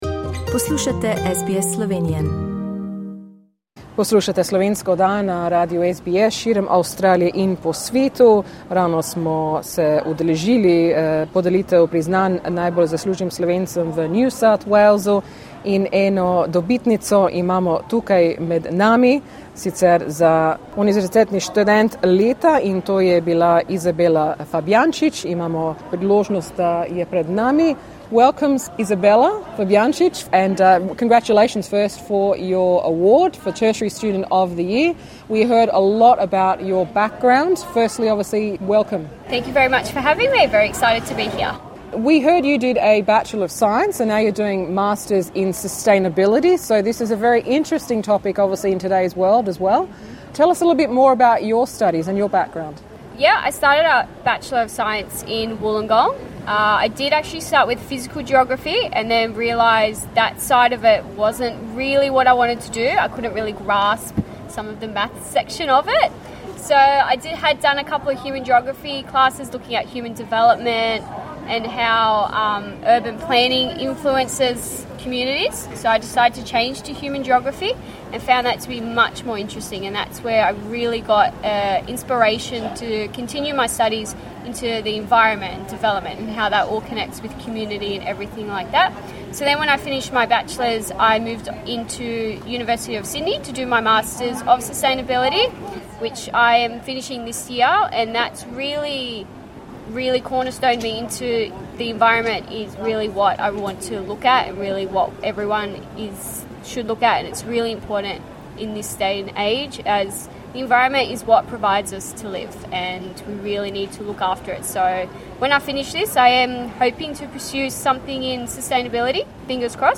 Z njo smo se pogovarjali po podelitvi.